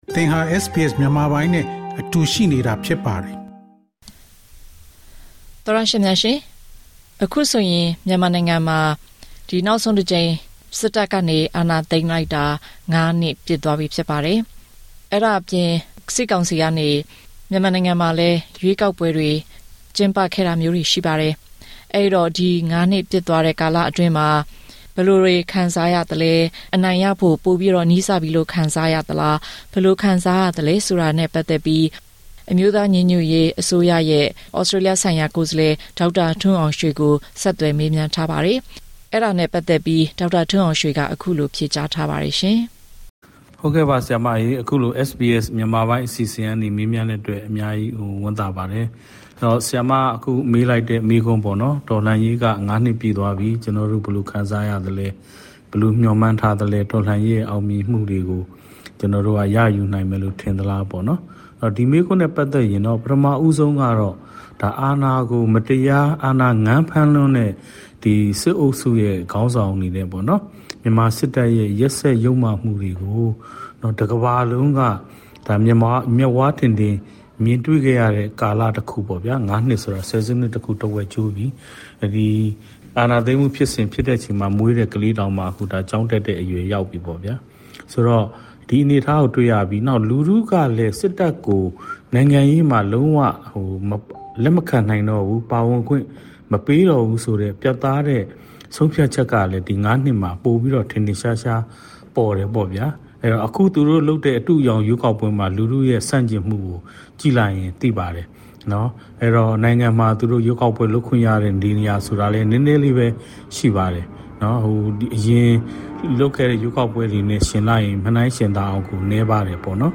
ဒီလို အနေအထားမှာ အတိုက်အခံတွေရဲ့ အနေအထားက ဘယ်လို ရှိသလဲ၊ နိုင်ငံတကာက အာဆီယံရဲ့ ဘုံသဘောတူညီချက် ၅ ရပ်အပေါ် အခြေခံတဲ့ နိုင်ငံရေး ဖြေရှင်းချက်မျိုးကို လိုလားနေသေးတာတွေနဲ့ နိုင်ငံတကာမှာ မြန်မာ့အရေး မှေးမှိန်လာရတဲ့ အနေအထားနဲ့ ပတ်သက်ပြီး ဘယ်လို ခံစားရသလဲ၊ ဘယ်လို သုံးသပ်သလဲ ဆိုတဲ့အကြောင်း အမျိုးသားညီညွတ်ရေး အစိုးရ အန်ယူဂျီရဲ့ ဩစတြေးလျ ကိုယ်စားလှယ် ‌‌ဒေါက်တာ ထွန်းအောင်ရွှ‌ေ နဲ့ ဆက်သွယ်မေးမြန်းထားတာကို နားဆင်နိုင်ပါပြီ။